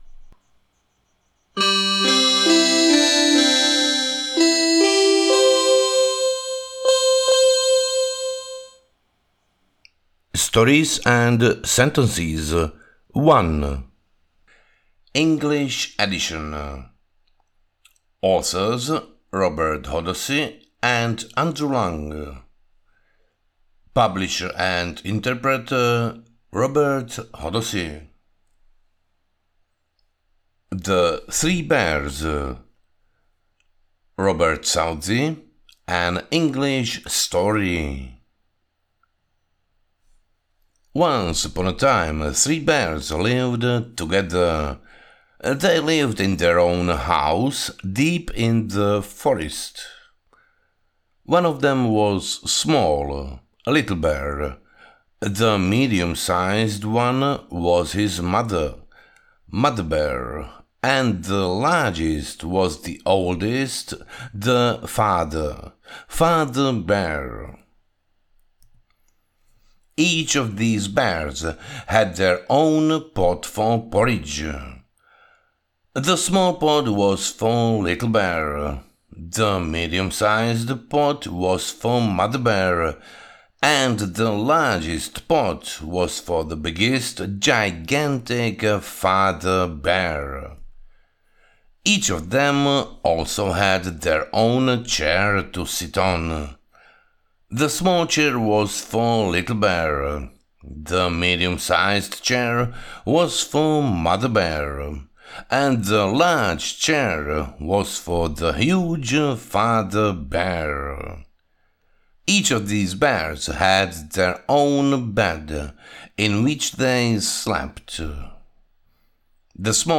Stories and Sentences 1: English Edition (Poviedky a vety 1: Anglické vydanie) je pútavá audiokniha v angličtine! Ponorte sa do 17 nadčasových rozprávok a bájok, vrátane klasík ako Tri medvede, Škaredé káčatko a Červená čiapočka, ideálnych na rozprúdenie fantázie a rozvoj anglických jazykových zručností.